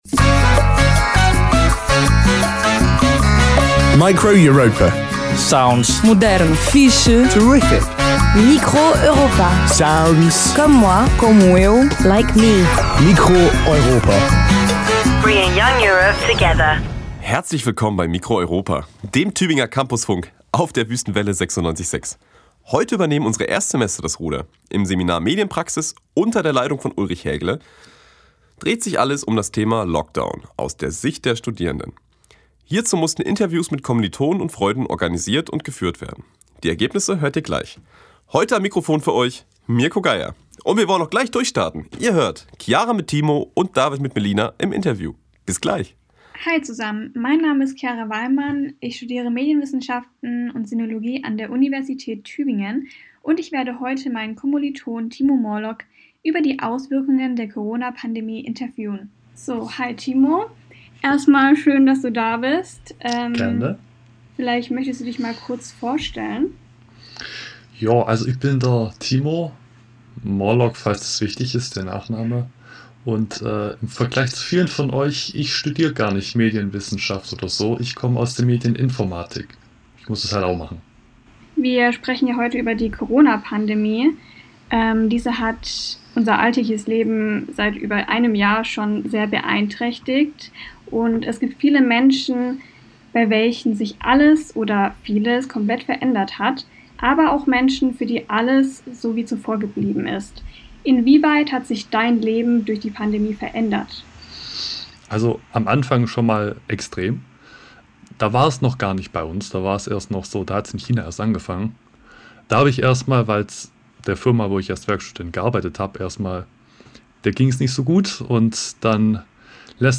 Form: Live-Aufzeichnung, geschnitten